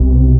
ORGAN-22.wav